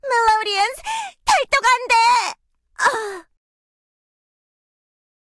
Melody Sad Emote Bouton sonore